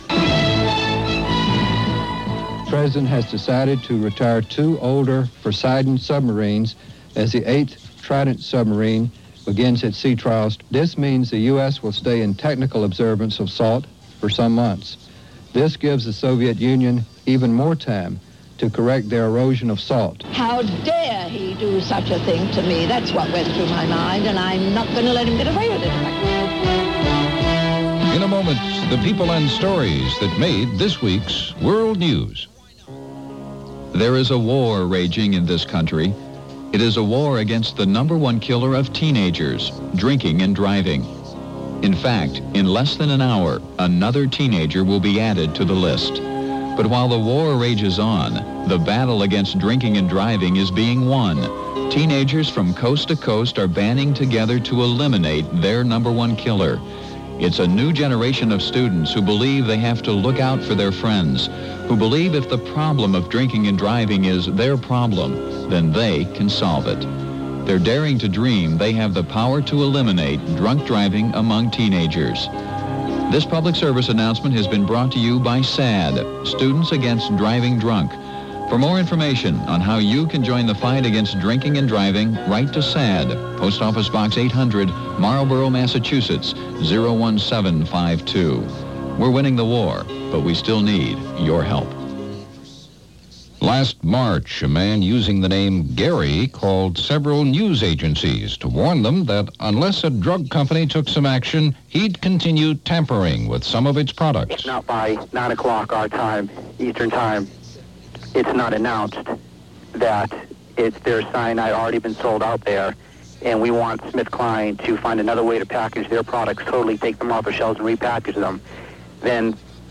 June 1, 1986 - Erosion Of SALT - The Tampering Of Capsules - The Holding Of Hands - news for this week in 1986 - Past Daily.